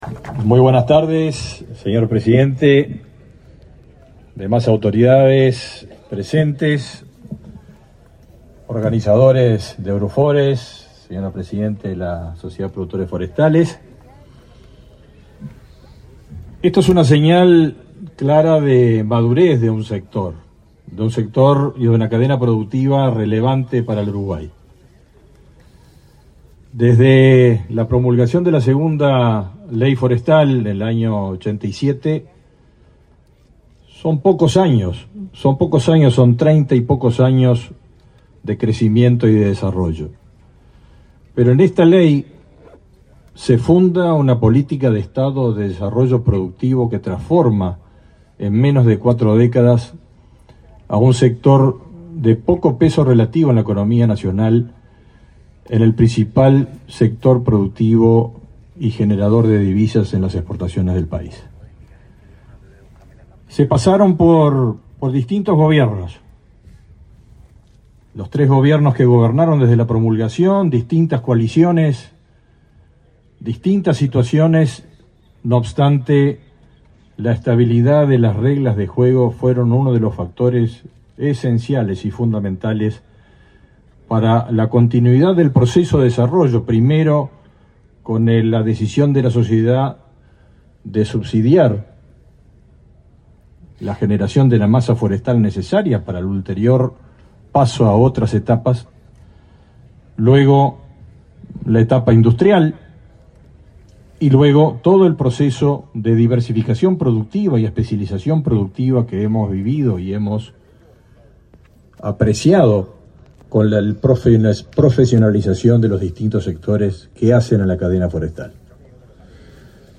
Palabras del titular del MGAP, Fernando Mattos 10/10/2024 Compartir Facebook X Copiar enlace WhatsApp LinkedIn El presidente de la República, Luis Lacalle Pou, participó, este 10 de octubre, en la Feria Forestal, del Agro y el Ambiente, en la ciudad de Juan Lacaze, en Colonia. En el evento disertó el titular del Ministerio de Ganadería, Agricultura y Pesca (MGAP), Fernando Mattos.
mattos oratoria.mp3